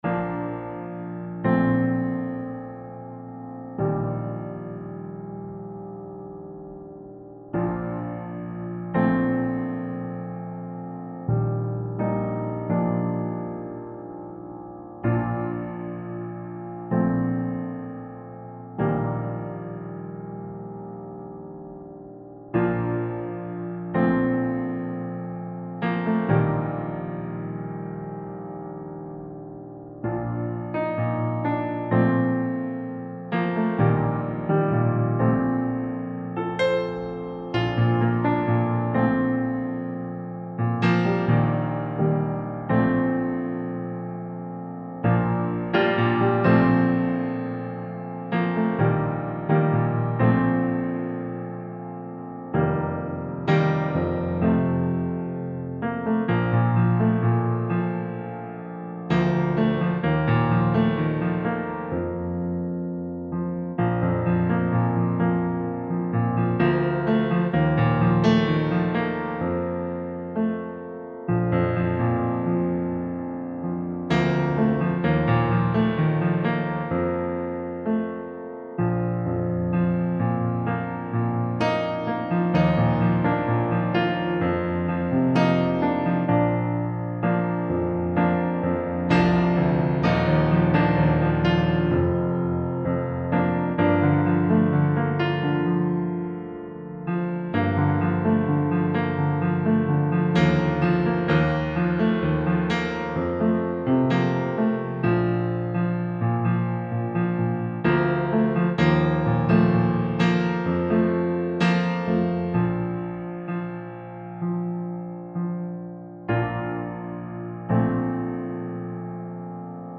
bcc-031814-warm-piano-ballad-891.mp3